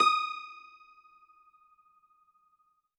53r-pno19-D4.wav